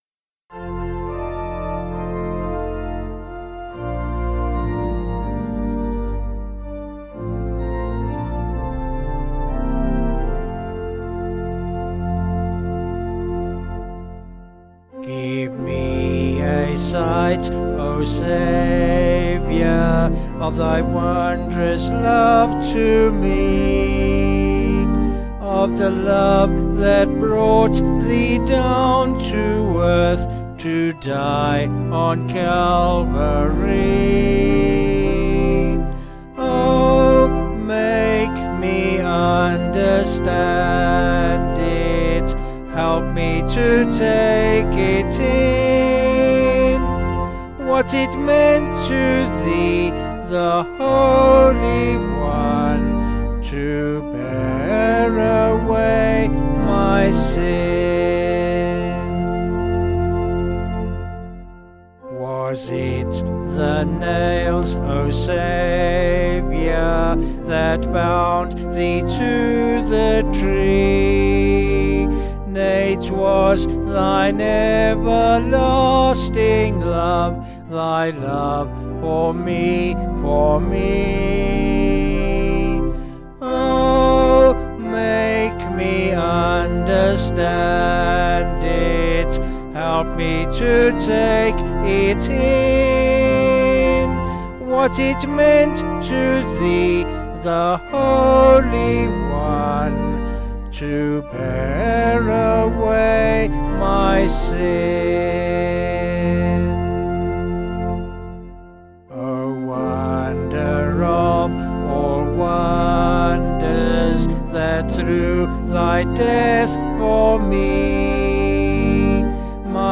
Vocals and Organ   705.1kb Sung Lyrics